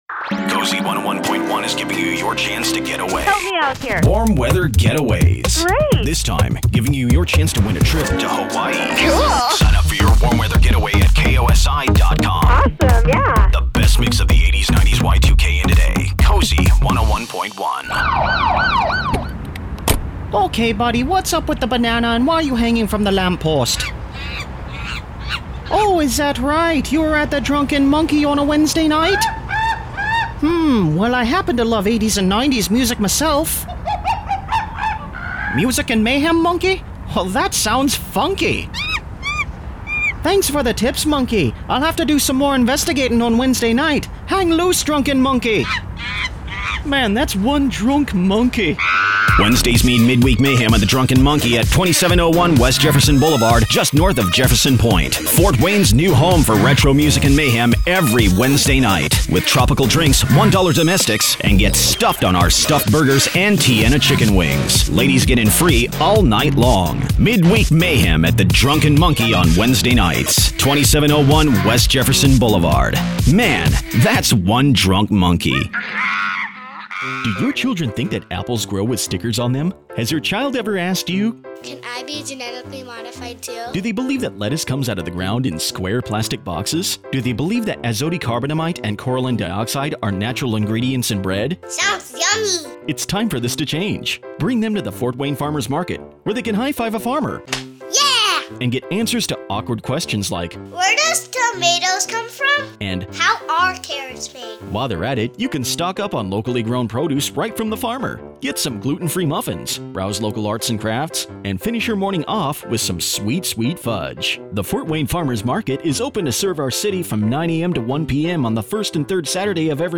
Versatile, Engaging, and Professional Voiceover Talent
Radio Commercial Montage
With over 25 years in media, I bring a rich, dynamic voice that elevates brands and captivates audiences.